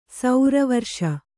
♪ saura varṣa